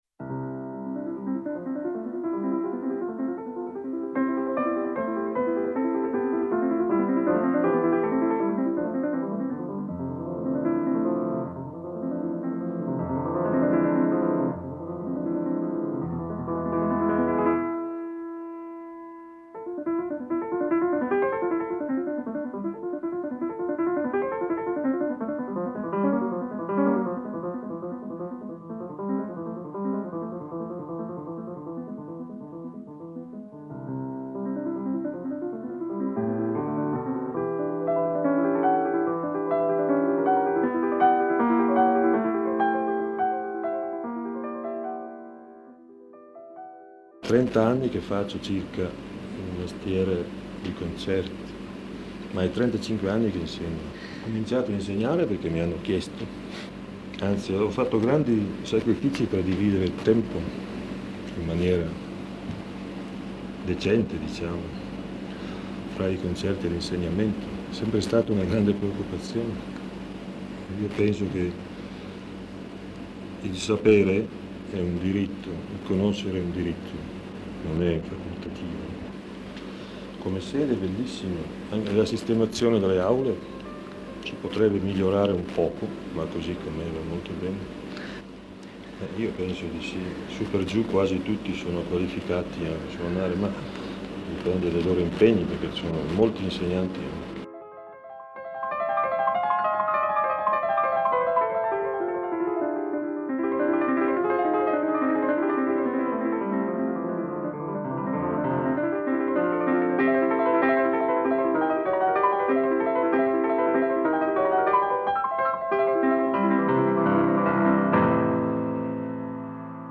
ascoltando documenti d’epoca